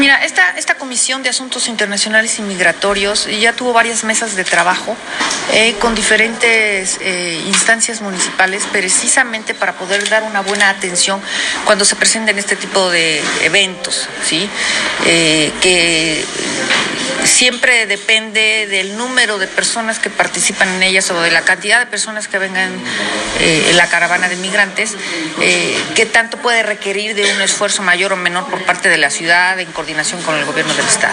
En entrevista, la regidora abundó con relación al tema de los migrantes que suelen pasar por la ciudad de Puebla ,debido a que nunca se sabe aproximadamente la cantidad de personas que llegan, por tanto, se buscará estar “vigilantes del paso que lleva la caravana migrante”.